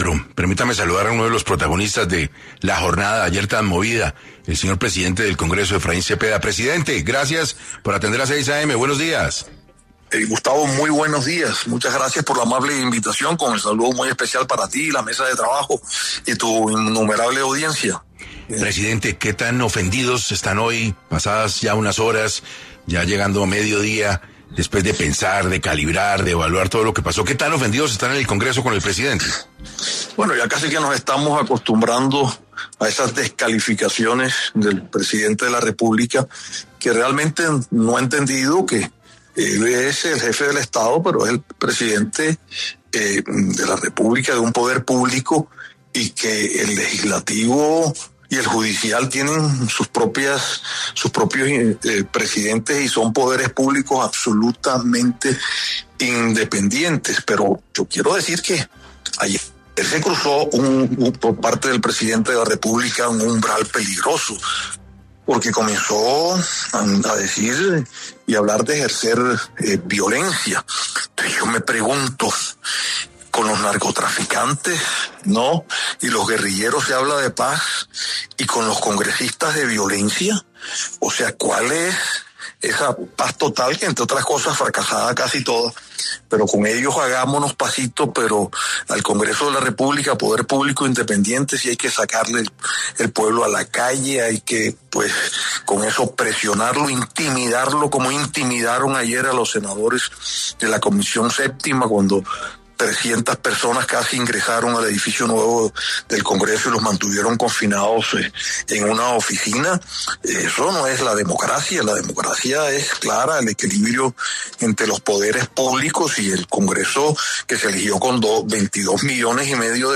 El presidente del Congreso, Efraín Cepeda, rechazó en 6AM de Caracol Radio el llamado a una consulta popular para la reforma laboral, calificándolo como un “mensaje peligroso” y una incitación a la confrontación.